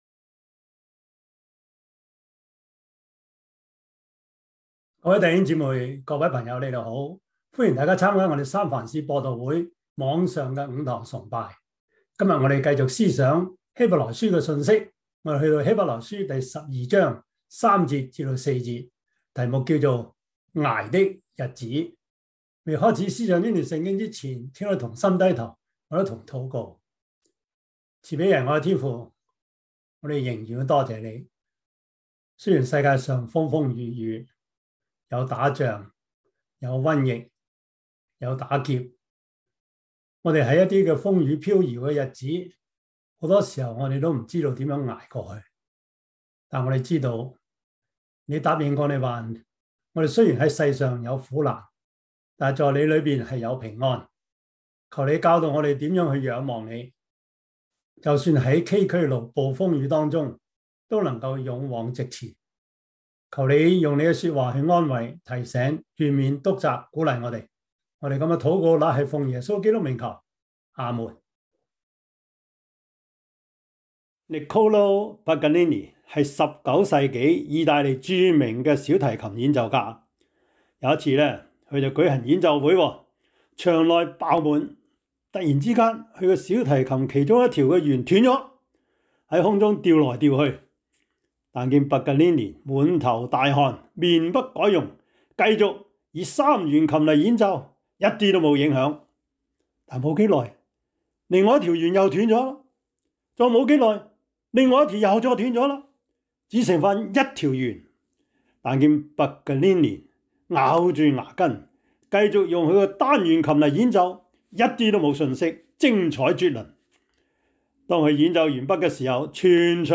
Topics: 主日證道 « 死亡被吞滅 摩西五經 – 第十五課 »